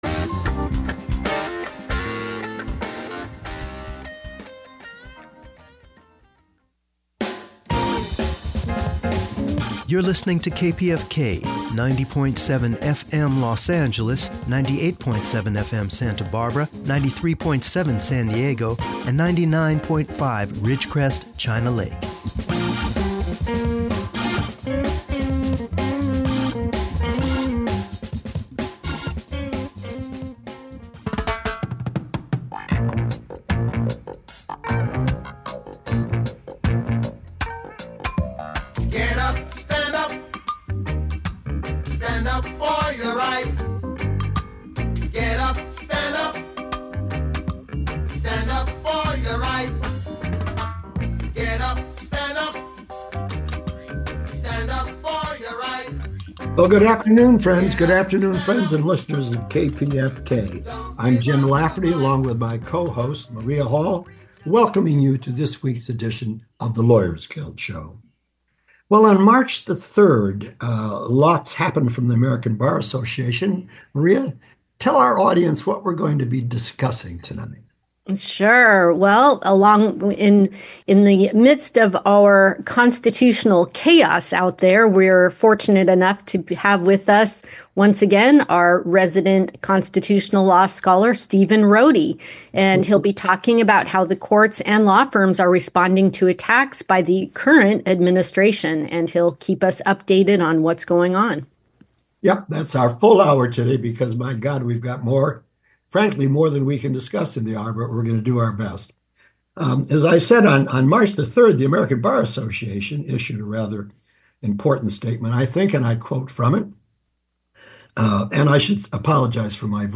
A public affairs program where political activists and experts discuss current political developments and progressive movements for social change.